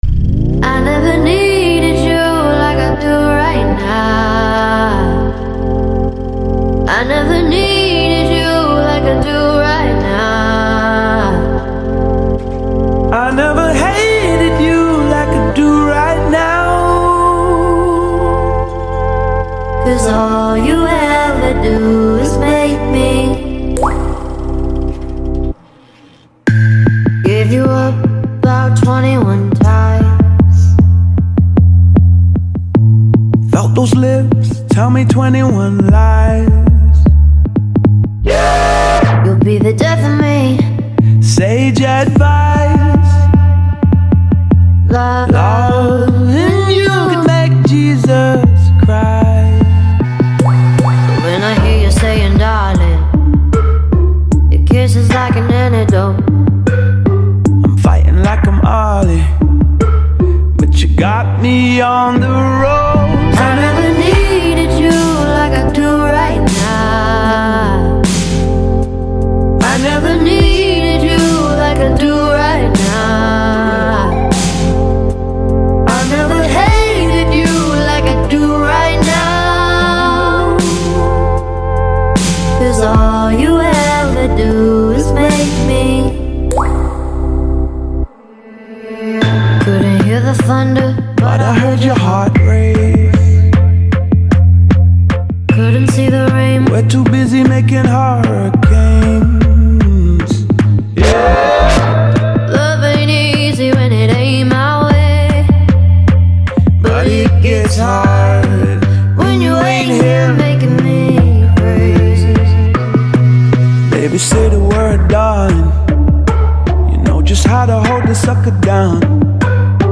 это эмоциональная поп-баллада